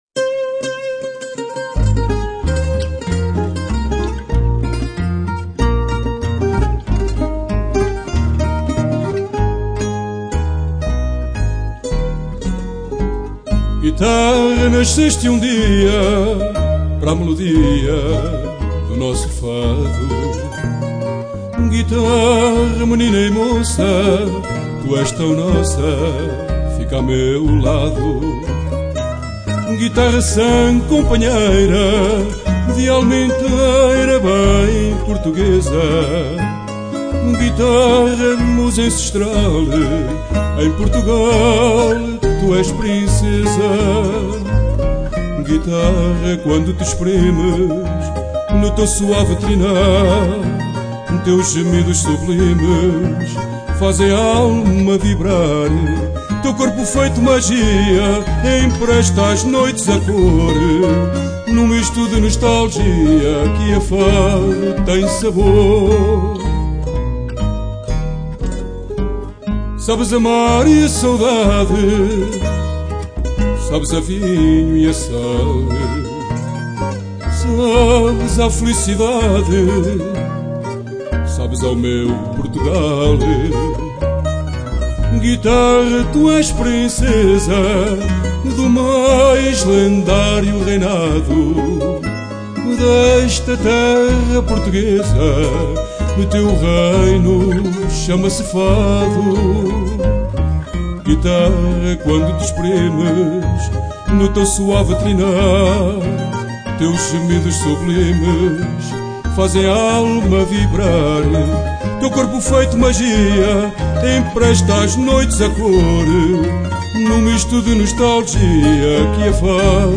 Fado_Guitarra_tu_es_Princesa.mp3